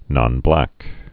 (nŏn-blăk)